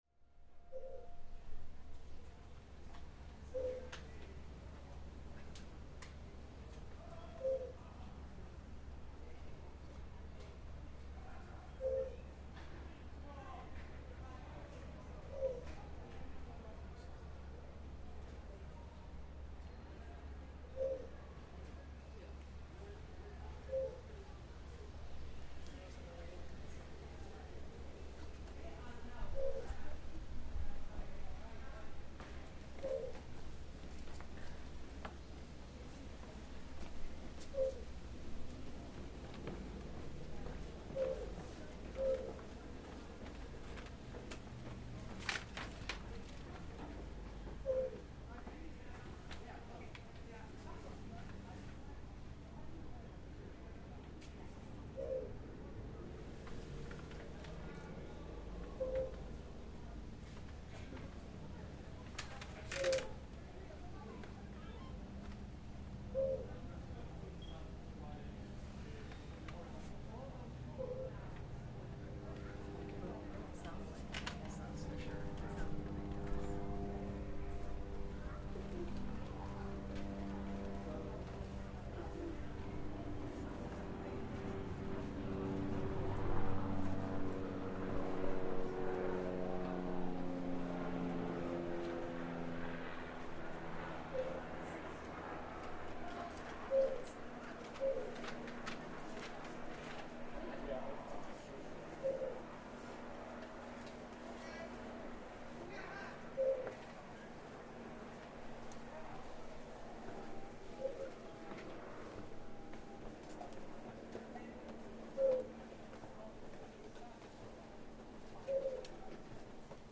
Having spent the afternoon around the Tower trying to capture the calls of the ravens among the thousands of tourists, we finally get lucky. Two of the ravens are perched on a quiet section of wall away from the crowds, and we're able to get up close with the microphone to hear them clucking and cooing, communicating with us and each other for a few special moments.